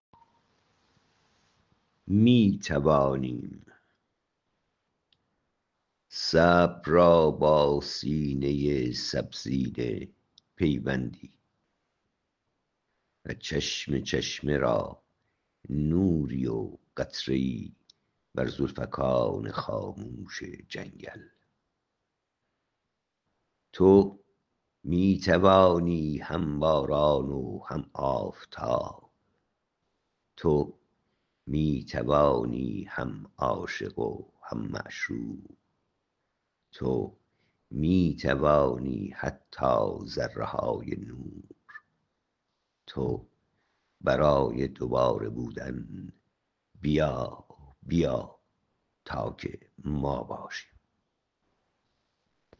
این شعر را با صدا شاعر بشنوید